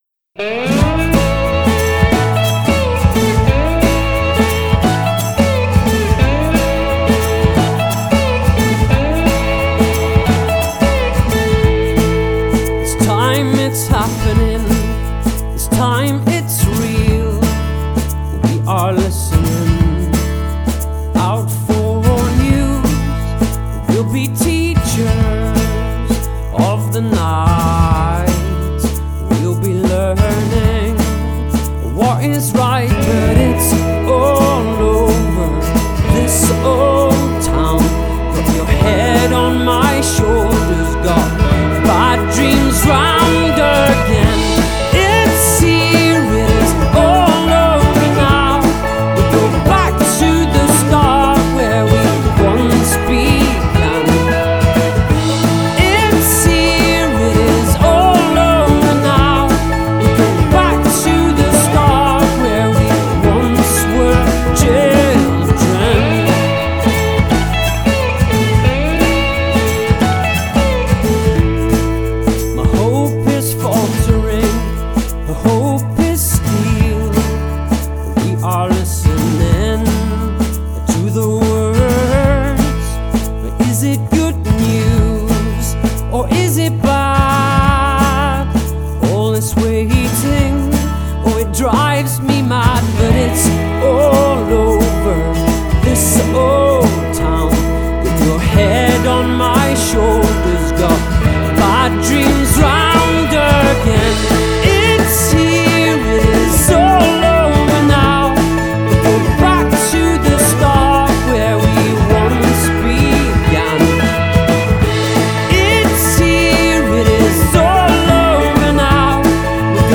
Genre: Indie Rock/ Indie Folk